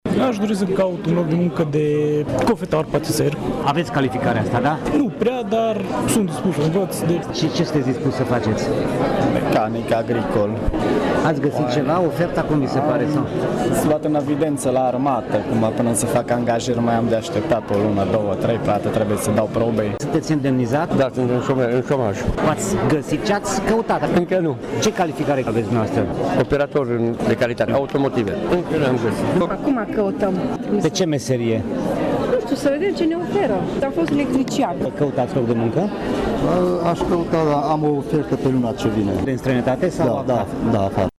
Nici angajații nu au fost foarte încântați de ofertele de muncă găsite astăzi la bursă: